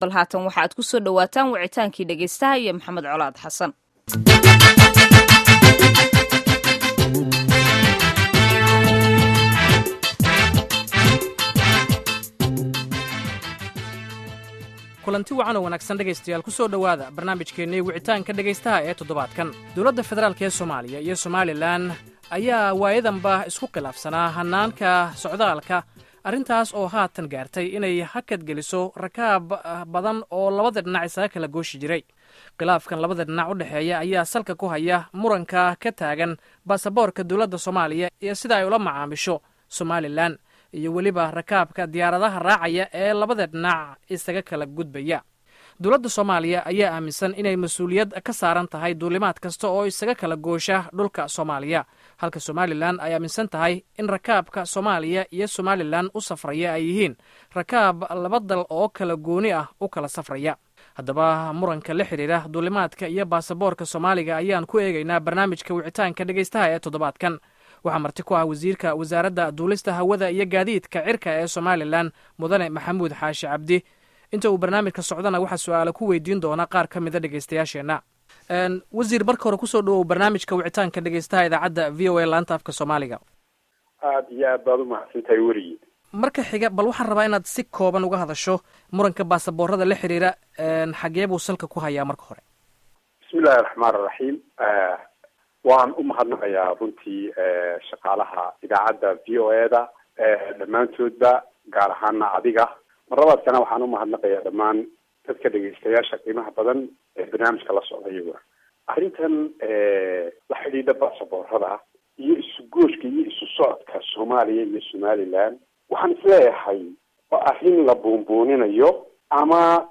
Barnaamijka Wicitaanka wuxuu ku saabsanyahay muranka dhinaca baasaboorka ee u dhaxeeya Somalia iyo Somaliland, waxaana ka jawaabaya su'aallaha wasiirka Duuliska Somaliland Maxamud Xaashi Cabdi